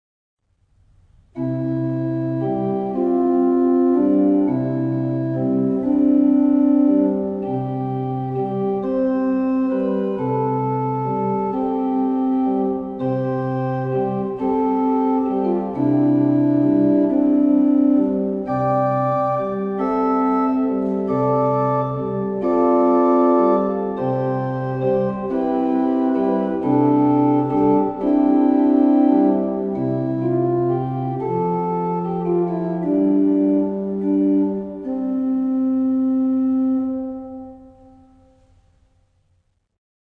Orgelvorspiele
Hier finden Sie einige Orgelvorspiele zu Liedern aus dem Gotteslob.
Gl_558_Ich_will_dich_lieben_Orgelvorspiel_1.mp3